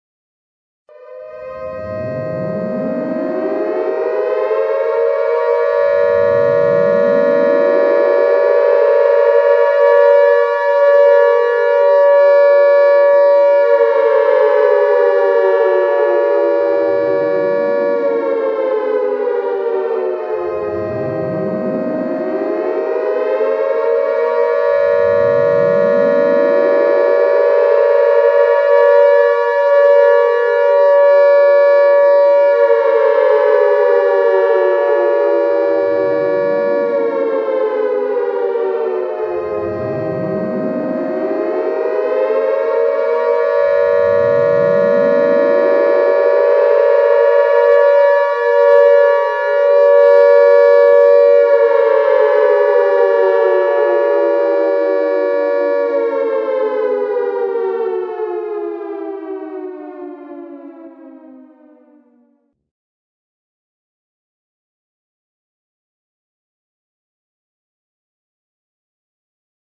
دانلود آهنگ آژیر خطر 3 از افکت صوتی طبیعت و محیط
جلوه های صوتی
دانلود صدای آژیر خطر 3 از ساعد نیوز با لینک مستقیم و کیفیت بالا